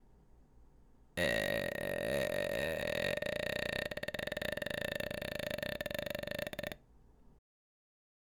次は最初のグーの声は使わず、最低限のパワーでじりじり音が鳴る仮声帯の寄せ具合を探していきましょう。母音は「え」で行います。
※見本音声